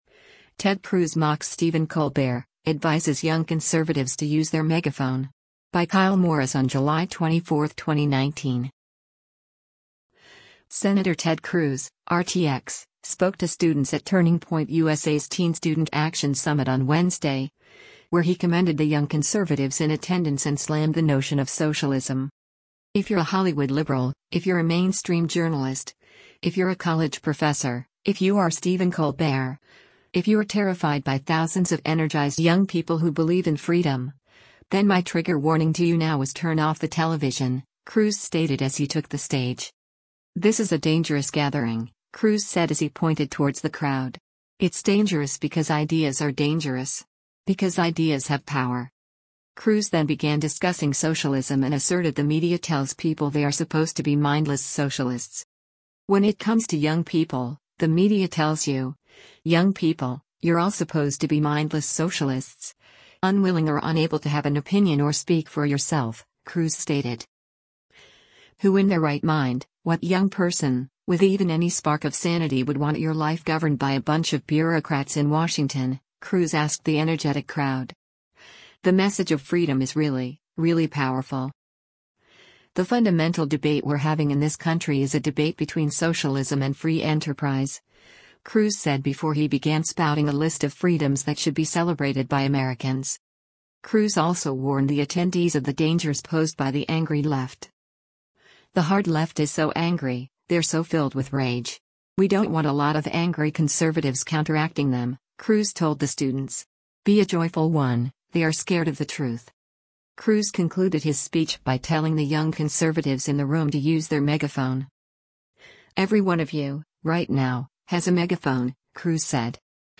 Sen. Ted Cruz (R-TX) spoke to students at Turning Point USA’s Teen Student Action Summit on Wednesday, where he commended the young conservatives in attendance and slammed the notion of socialism.
“Who in their right mind, what young person, with even any spark of sanity would want your life governed by a bunch of bureaucrats in Washington,” Cruz asked the energetic crowd.